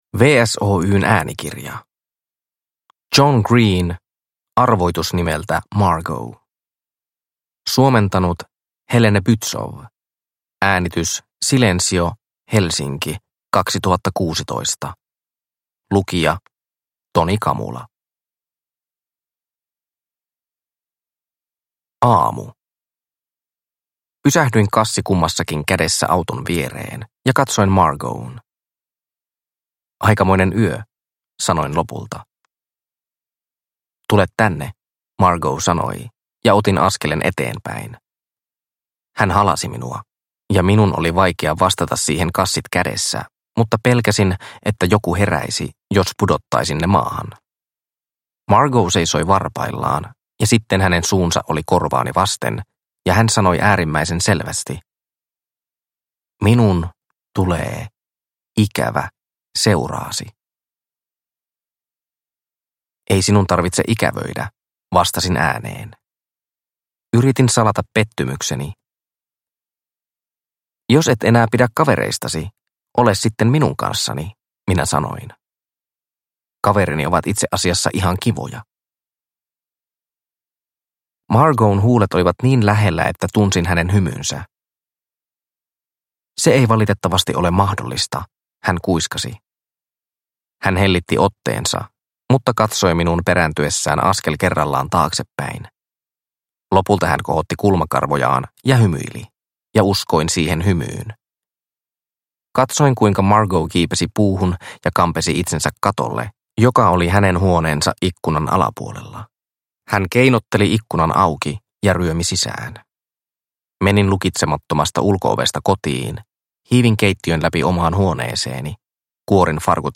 Arvoitus nimeltä Margo (ljudbok) av John Green